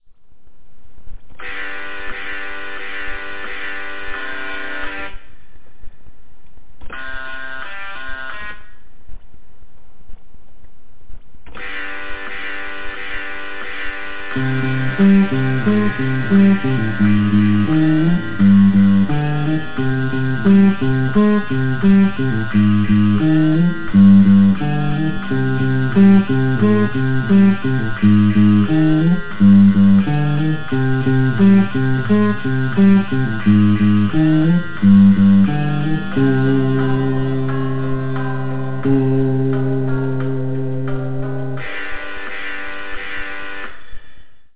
Ho suonato decisamente peggio del solito!